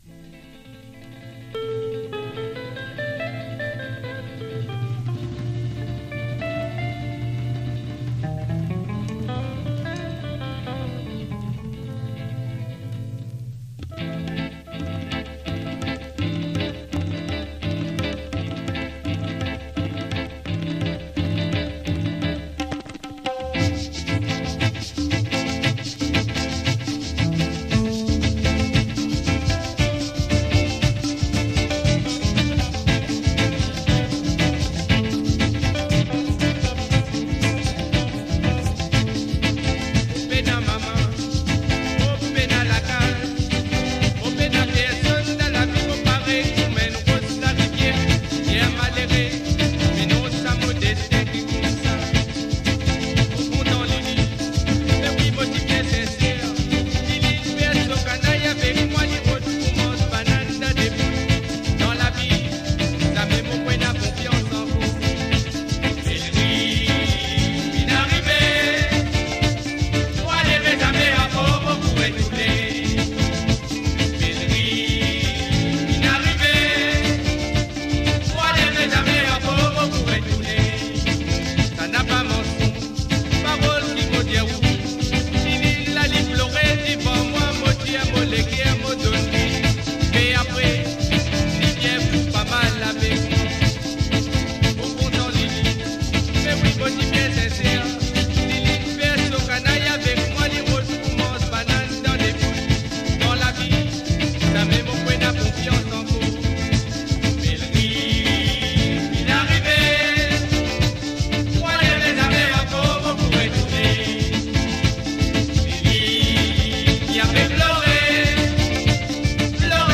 Mauritius , 1970s